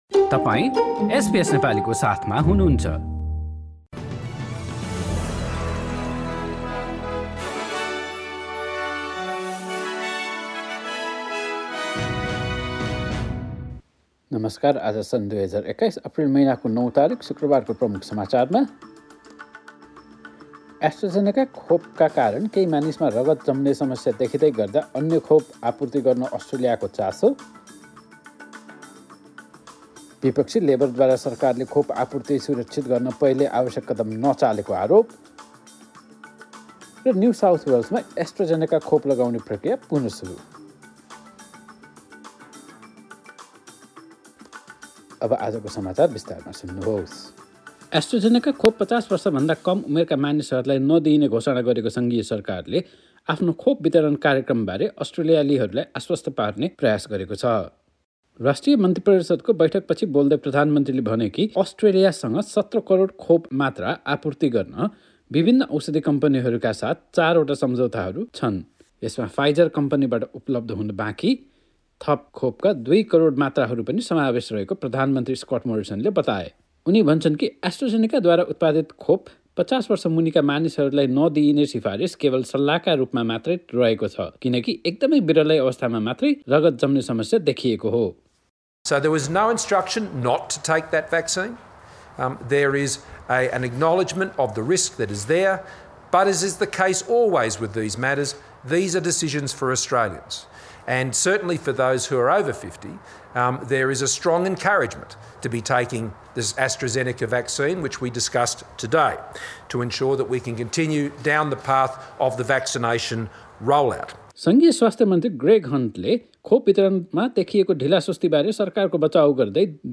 Listen to latest news headline from Australia in Nepali.